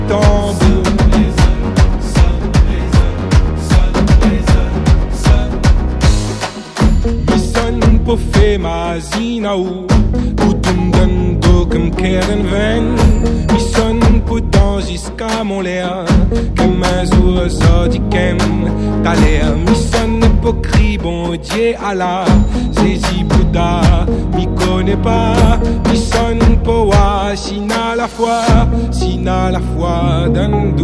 Musique du Monde